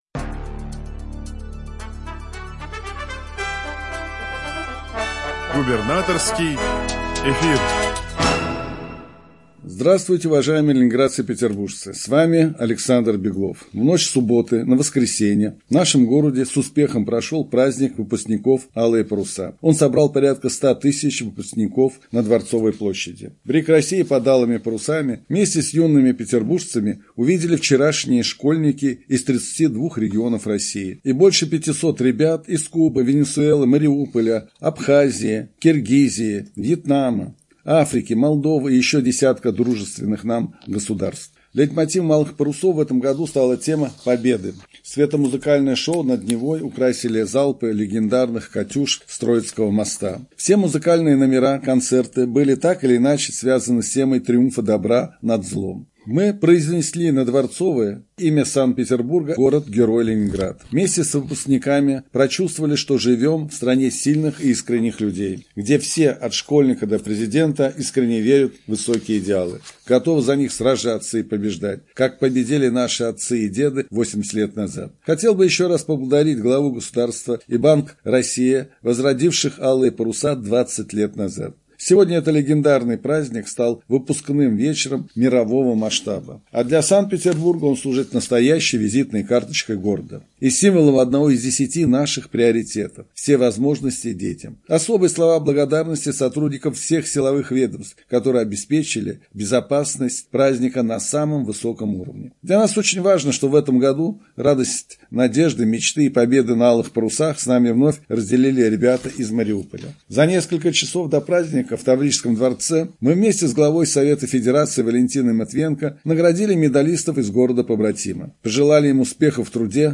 Радиообращение – 30 июня 2025 года